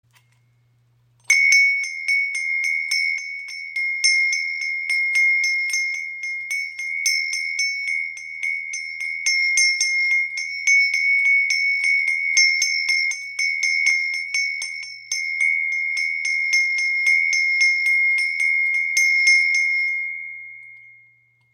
Tempelglocke aus Rishikesh · Die Kraft der sechs Ganeshas · ø 10 cm
Diese handgefertigte Tempelglocke aus Rishikesh ist mit sechs kunstvollen Ganesha-Figuren verziert, die Weisheit und Schutz symbolisieren. Ihr klarer Klang reinigt die Atmosphäre und öffnet den Geist.
Ein tiefer, klarer Klang durchdringt die Stille – sanft, doch kraftvoll.
• Material: Massives Messing, handgefertigt
• Höhe: 14.5 cm, ø 10 cm